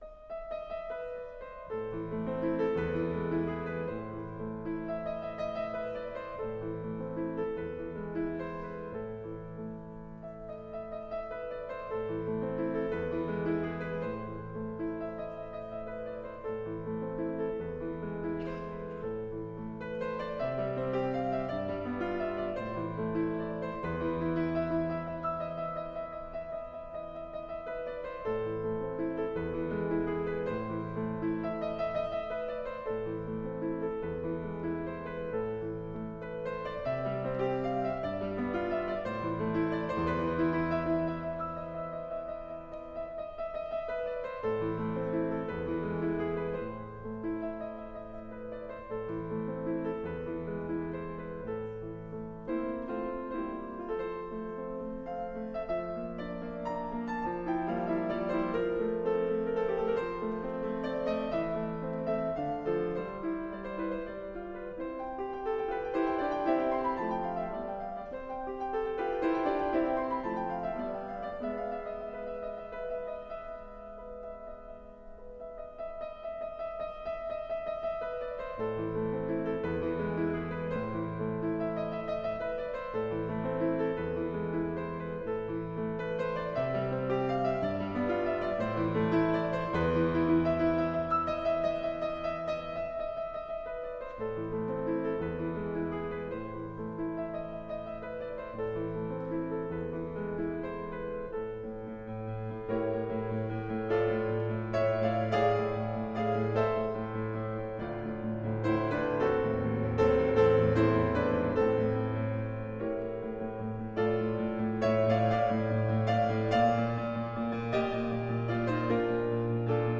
The selections below are from a concert I played called "The Essential Pianist".